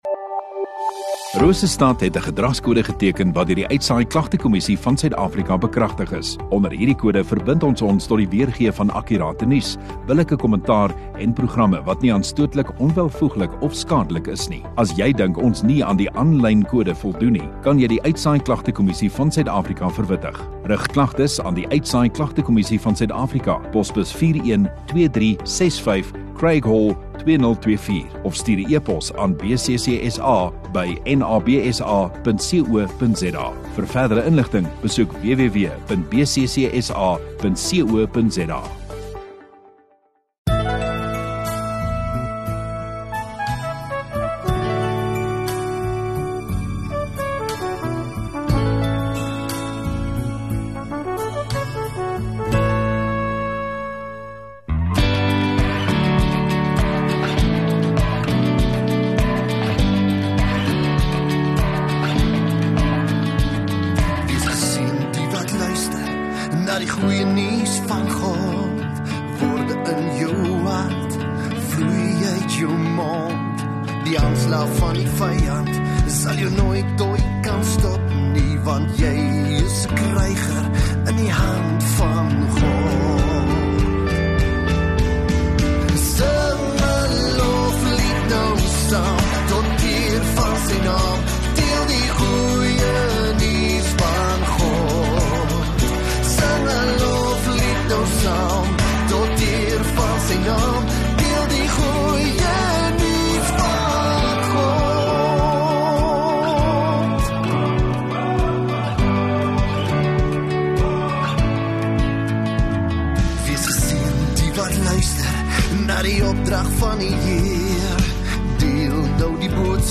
Sondagoggend Erediens